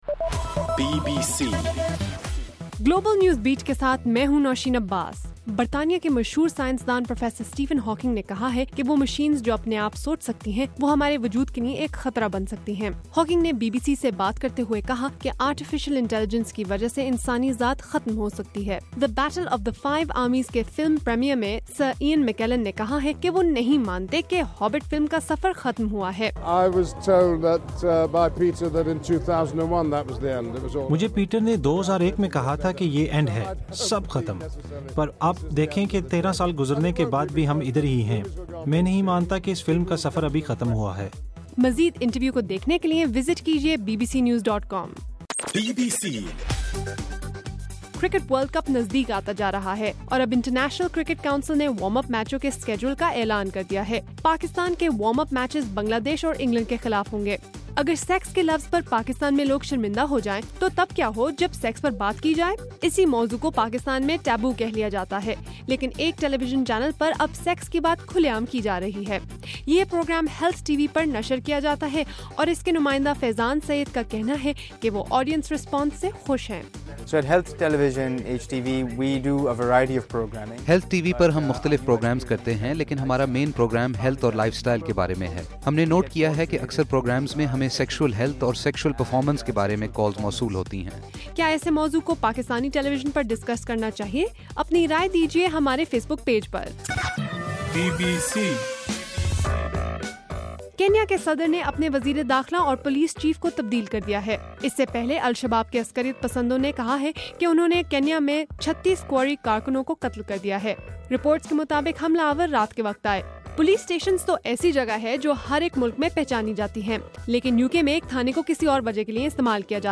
دسمبر 2: رات 12 بجے کا گلوبل نیوز بیٹ بُلیٹن